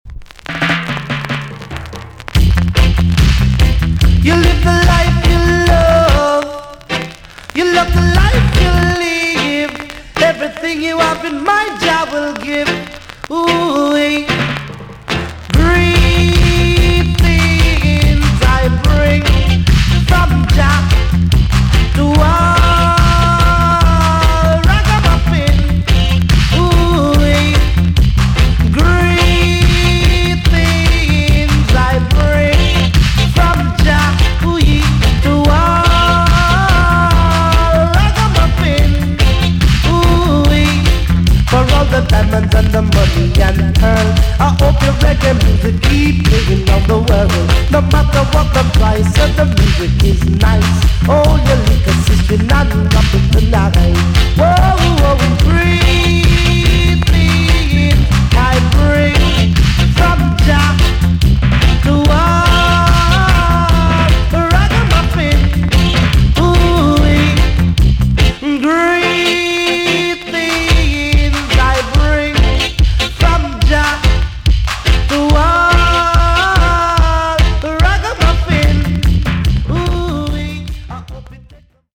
TOP >80'S 90'S DANCEHALL
VG ok 全体的に軽いチリノイズが入ります。